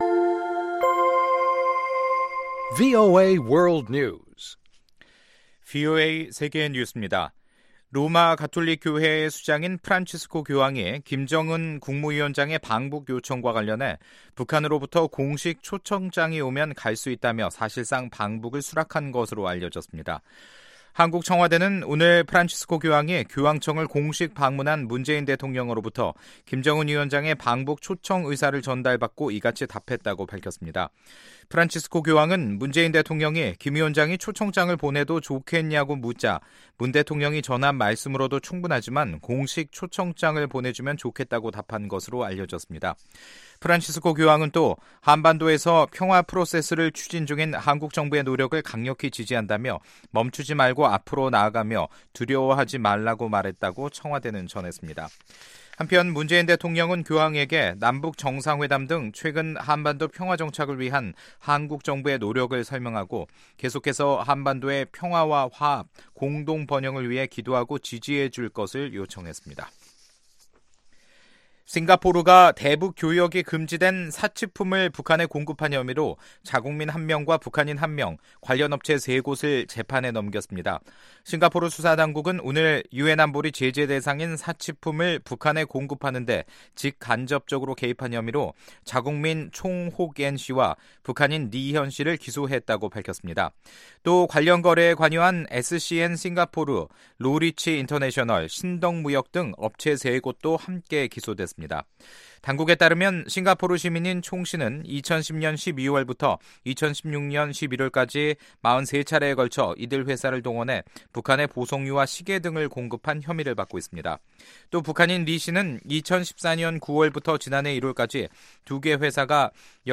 세계 뉴스와 함께 미국의 모든 것을 소개하는 '생방송 여기는 워싱턴입니다', 2018년 10월 18일 저녁 방송입니다. ‘지구촌 오늘’에서는 미국이 주요교역국의 환율정책 보고서를 발표한 가운데 당초 미-중간 무역 갈등으로 환율 조작국으로 지정될 가능성이 제기됐던 중국이 명단에서 빠졌다는 소식, ‘아메리카 나우’에서는 로드 로젠스타인 연방 법무부 부장관이 로버트 뮬러 특검 수사를 옹호했다는 이야기를 소개합니다.